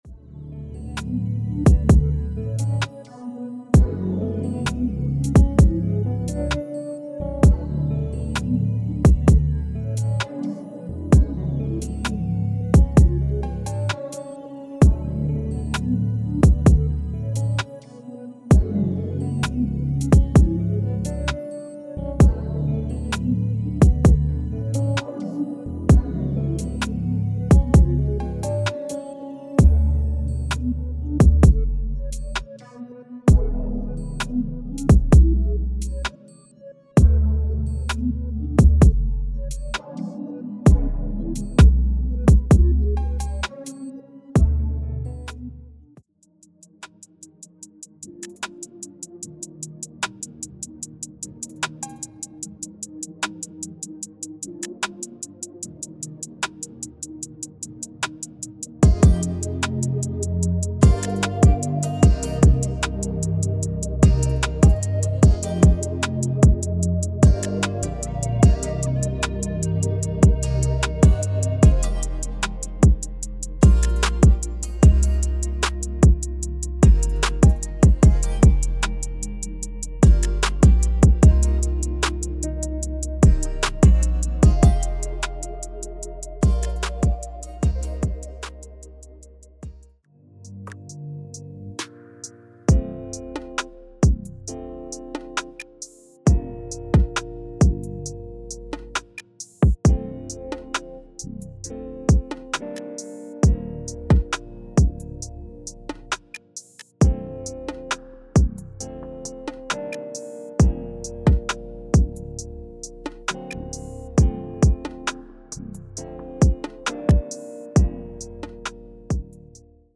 - 6 Pre-Composed Samples.